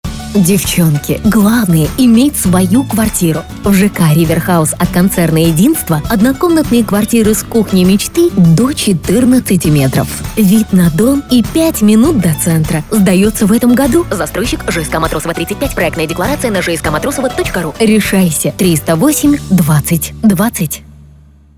Радиоролик был записан с доверительной интонацией, в формате дружеской беседы между подругами.